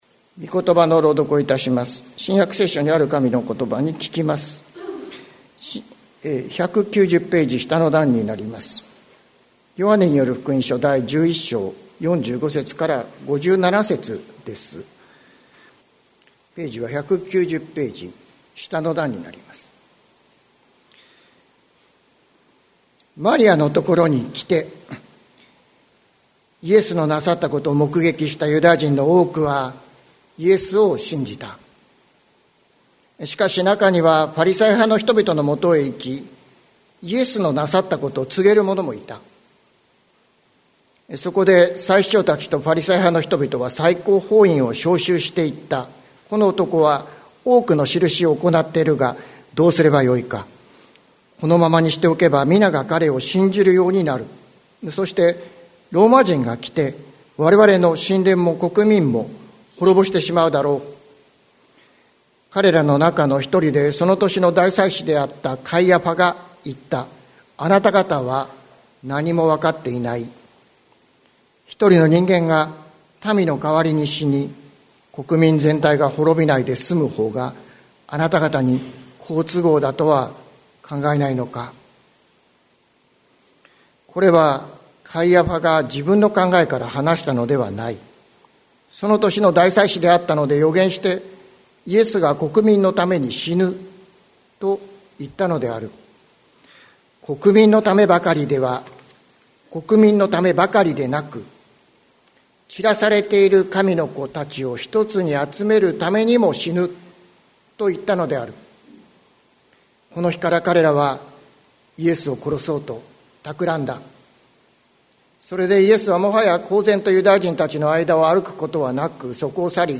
2023年02月12日朝の礼拝「わたしのために死なれた方」関キリスト教会
説教アーカイブ。